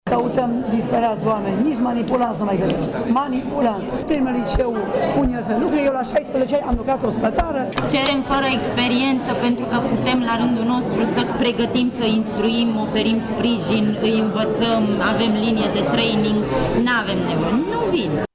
Angajatorii se plâng că cei care vin la bursă nu sunt interesați cu adevărat de oferte, ci doar tatonează piața:
stiri-12-apr-voxuri-angajatori.mp3